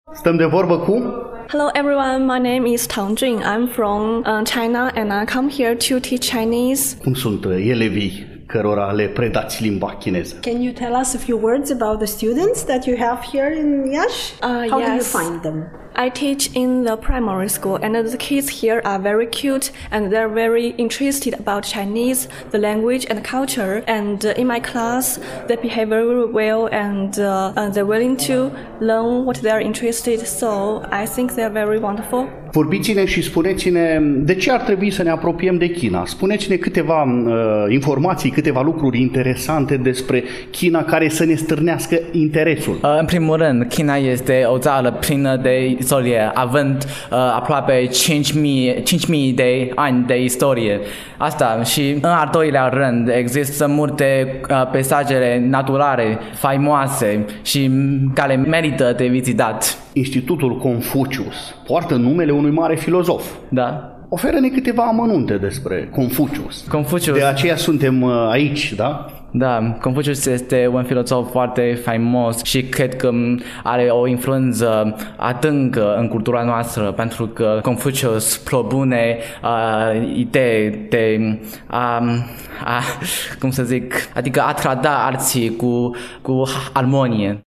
Dacă în două ediții trecute ale emisiunii noastre am vorbit despre Anul Nou Chinezesc, cu accent pe câteva activități culturale organizate cu acest prilej atât în incinta Fundației EuroEd din Iași, cât și în incinta Universității de Medicină și Farmacie „Gr. T. Popa” Iași, astăzi continuăm a difuza câteva înregistrări din timpul sărbătorii pe care am amintit-o.